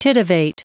Prononciation du mot titivate en anglais (fichier audio)
Prononciation du mot : titivate